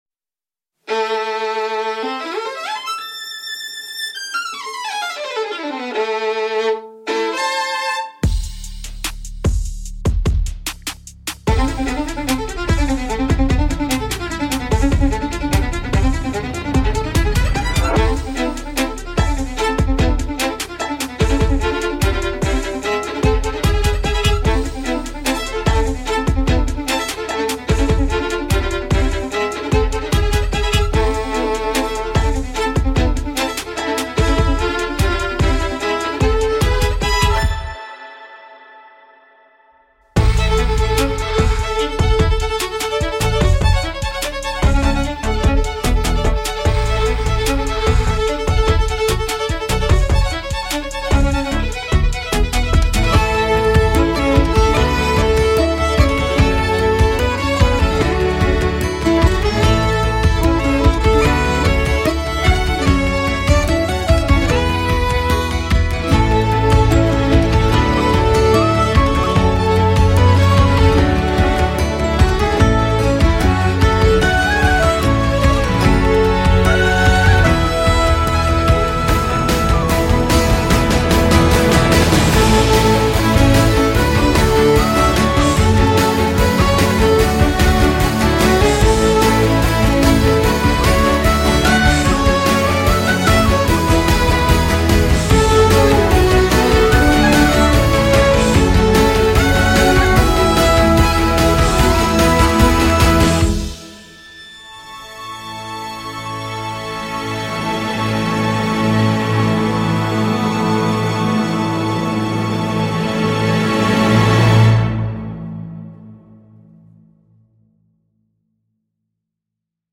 Voilà ce que doit être une « feelgood music ».